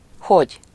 Ääntäminen
Ääntäminen France (Paris): IPA: /kɔ.mɑ̃/ Tuntematon aksentti: IPA: /kɔm/ Haettu sana löytyi näillä lähdekielillä: ranska Käännös Ääninäyte Adjektiivit 1. hogy Muut/tuntemattomat 2. milyen Comment on sanan commer taipunut muoto.